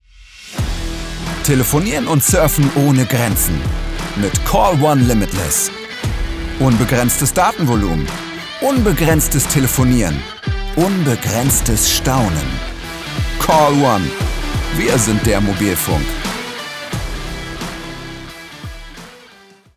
dunkel, sonor, souverän, plakativ
Mittel plus (35-65)
Werbung 03 - werblich
Commercial (Werbung)